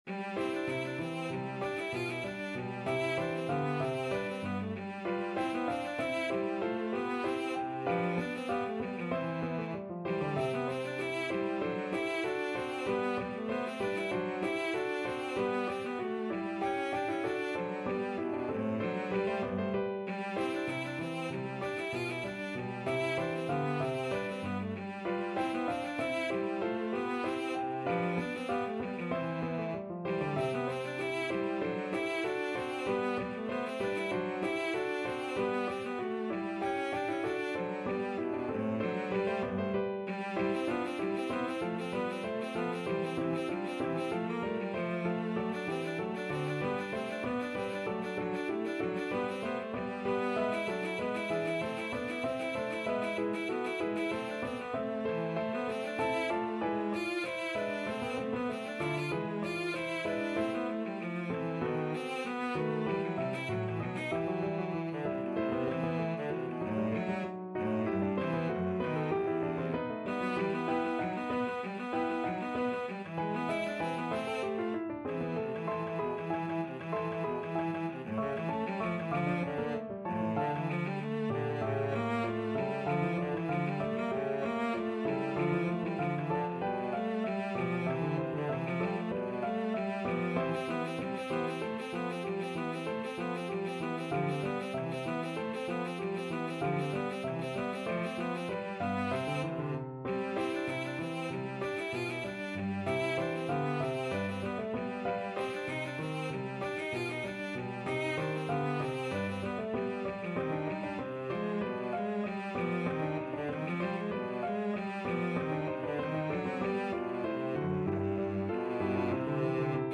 Free Sheet music for Cello
Cello
4/4 (View more 4/4 Music)
E3-F5
C major (Sounding Pitch) (View more C major Music for Cello )
II: Allegro =96 (View more music marked Allegro)
Classical (View more Classical Cello Music)